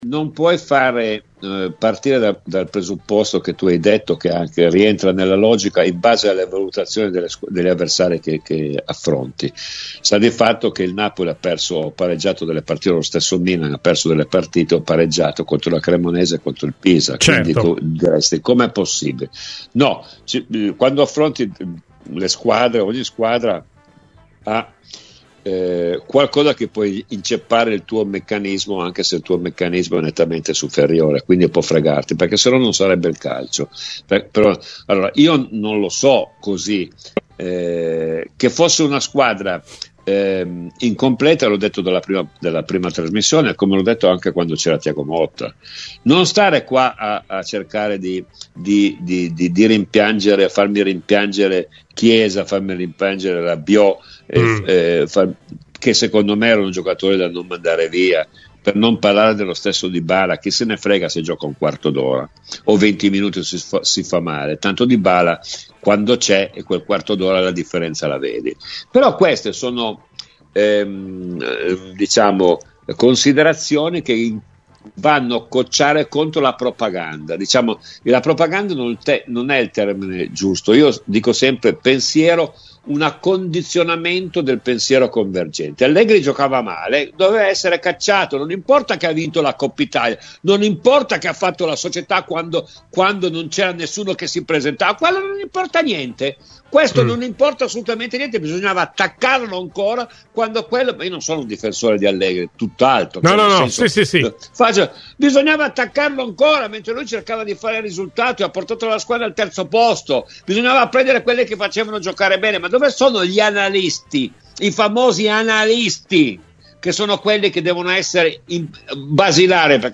Ospite di "Cose di Calcio" su Radio Biancoenra, Domenico Marocchino ha commentato il momento di difficoltà della Juventus, culminato in queste ore con l'esonero di Igor Tudor: "La Juventus non segna da quattro partite, ma il principale problema al momento non è l'attacco ma il centrocampo, e quindi la gestione della partita, che va gestita sia quando va bene che quando va male.